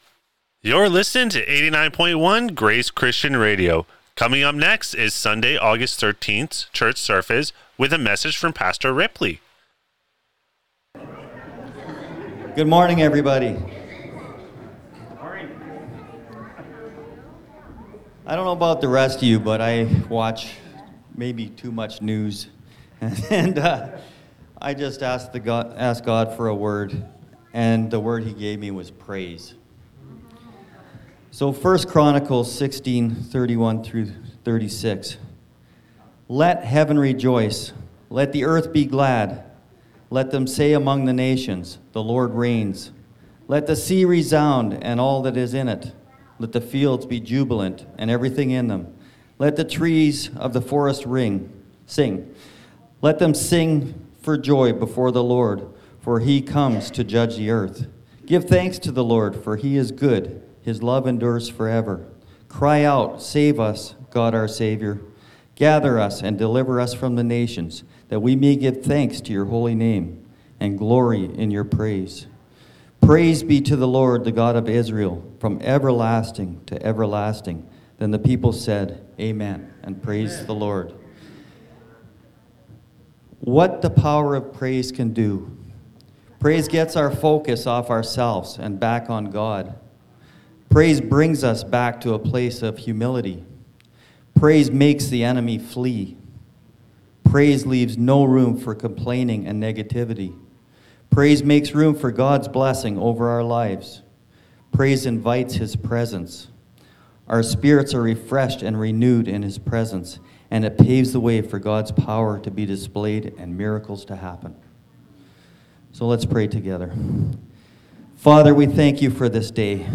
Sermons | Grace Christian Fellowship